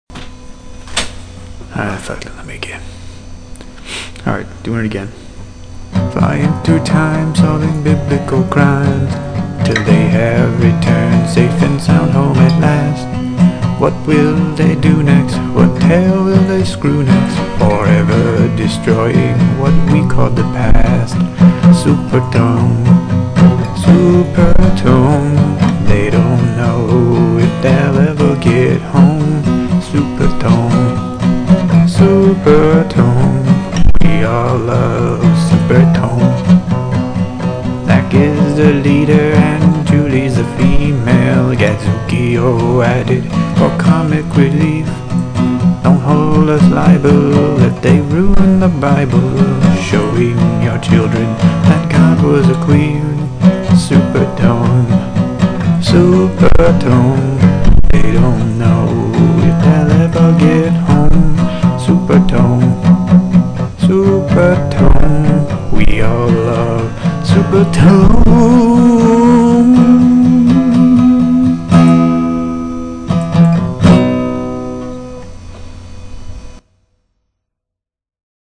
I apologize for my lack of ability to sing. But I do think I captured the pure 80s-esque nature of the final syllable, even if I do not have the MIDI synthesizers and chorus of children going "La-la-laaa la-la la la laaaa" that the cartoon used.